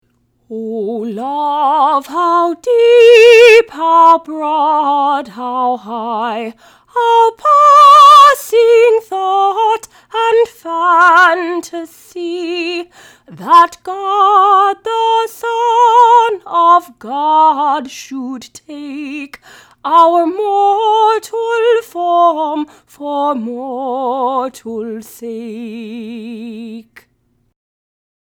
“ O Love, How Deep, How Broad, How High” Portuguese Text: Thomas á Kempis, c. 1450 Tune: Deus Tuorum Militum, 1753 Sing along with me to learn verse one of this hymn.